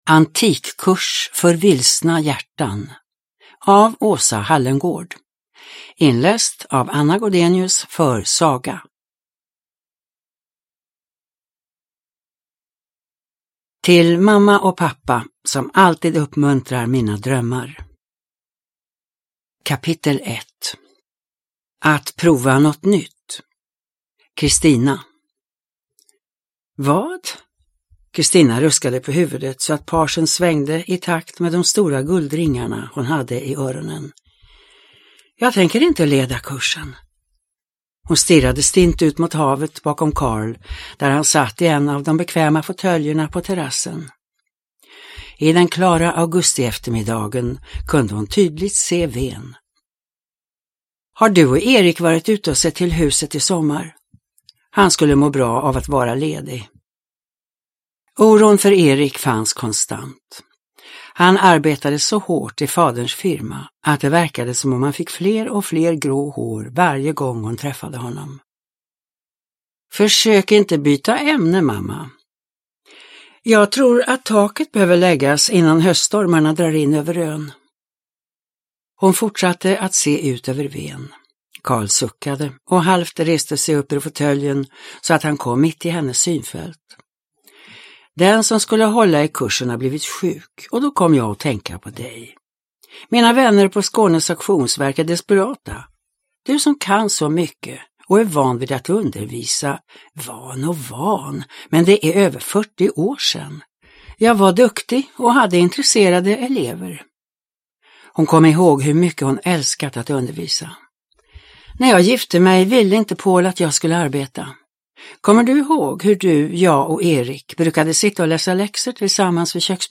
Antikkurs för vilsna hjärtan / Ljudbok